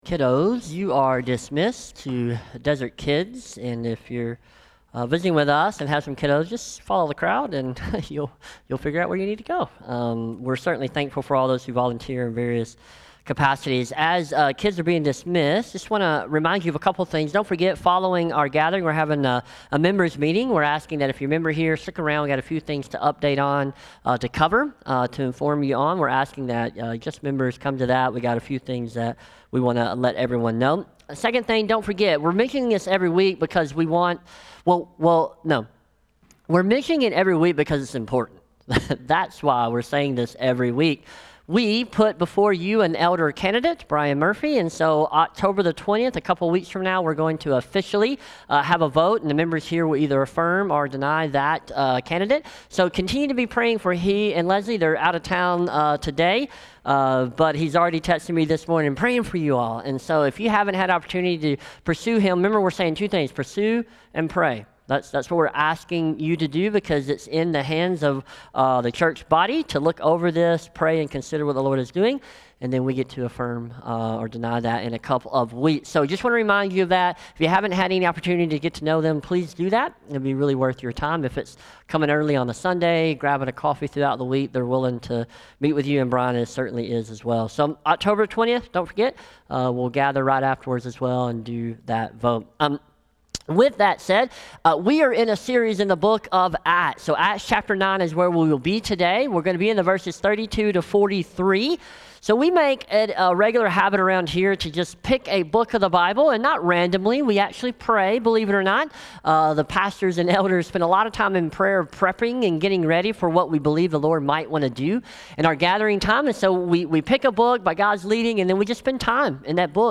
SERMON | Acts 9:32-43 | Peter Heals | Light in the Desert Church